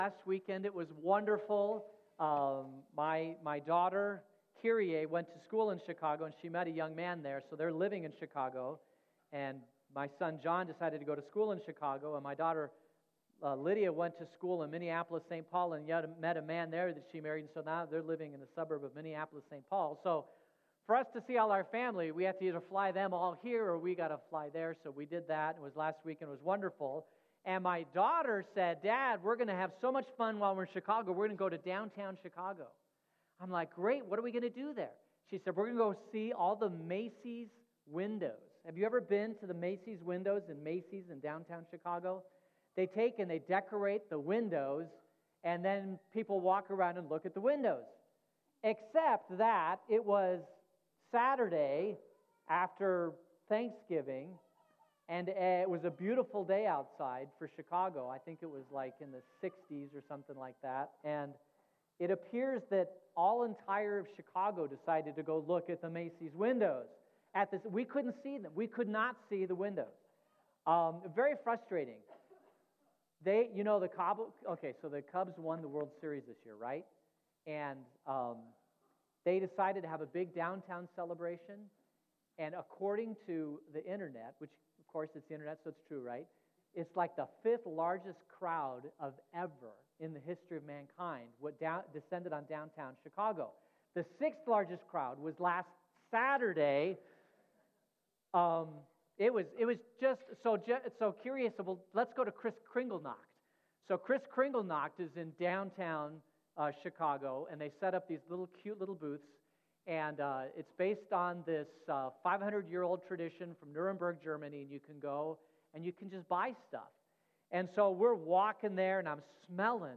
Advent Sermon Series Part 2: Christmas Shopping